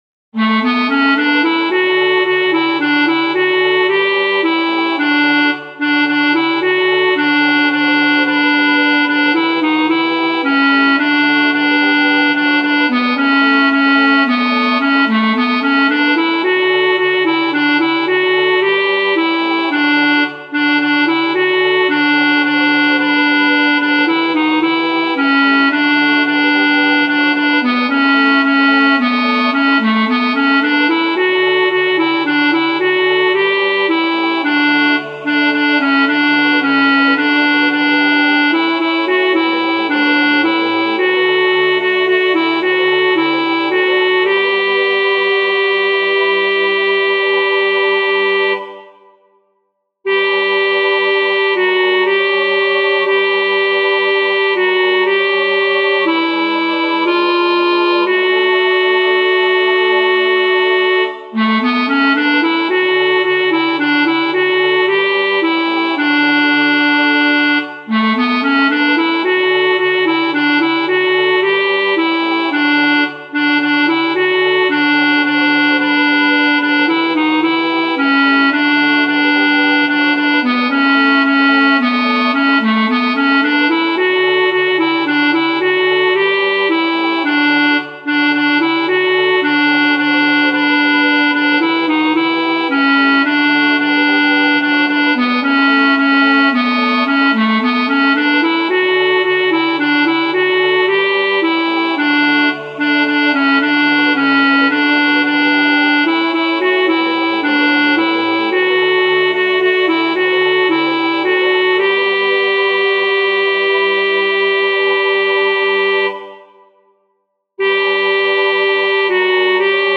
• El tempo indicado es Vivo.
Aquí os dejo los MIDI con las diferentes voces: